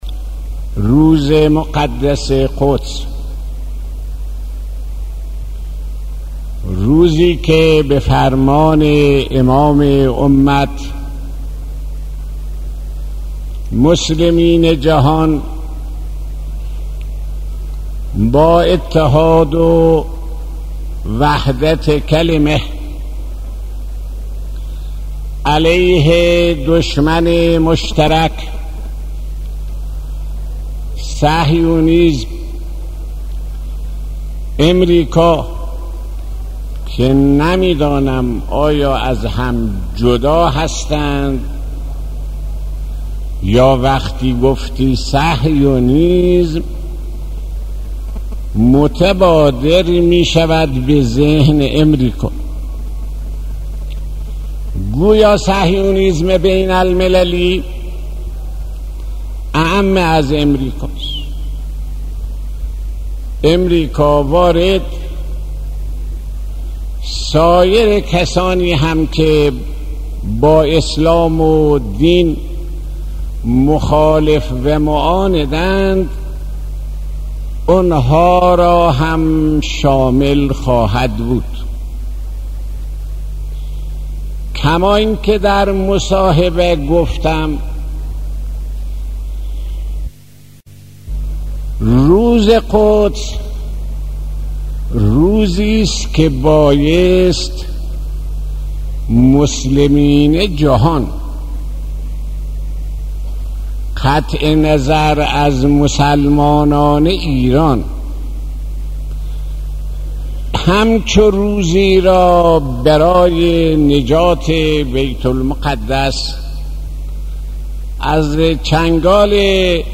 صوت/ سخناني از شهيد آيت الله صدوقى درباره اهمیت روز قدس